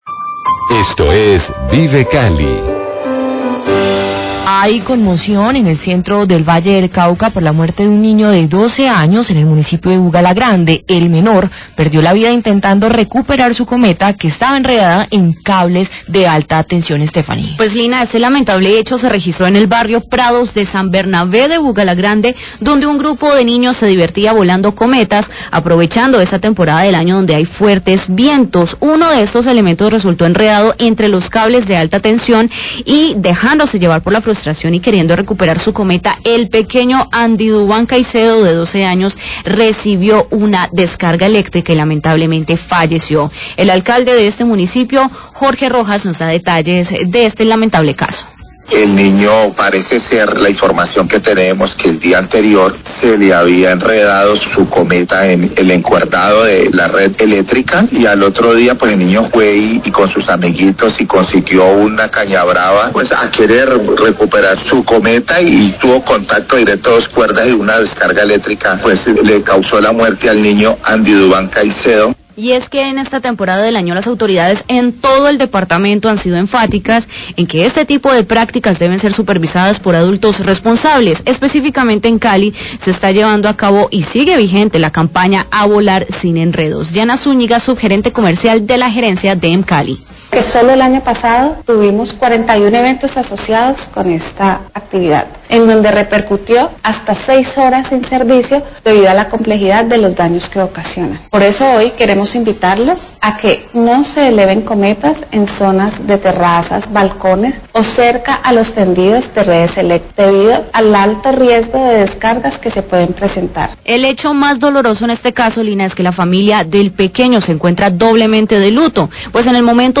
Radio
El alcade de Bugalagrande, Jorge Rojas, habla de la conmoción en su municipio por la muerte de un menor de edad por electrocución, cuando intentó bajar su cometa que había quedada atrapada en la red de alta tensión.Funcionaria de Emcali entrega algunas recomendaciones para evitar accidentes al elevar las cometas.